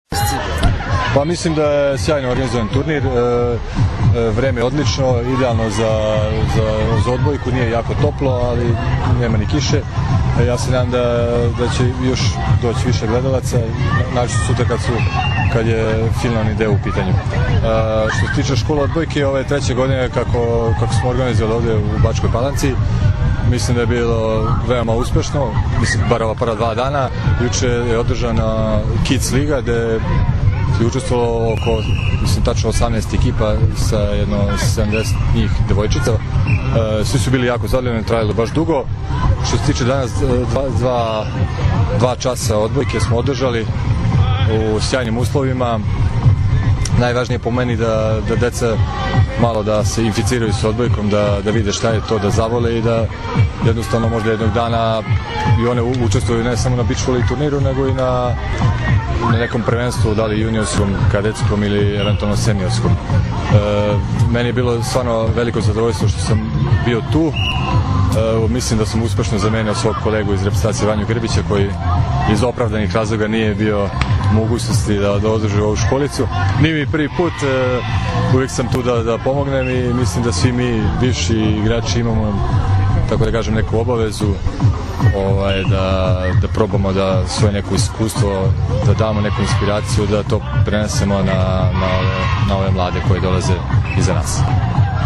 IZJAVA ĐULE MEŠTERA